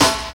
101 SNARE.wav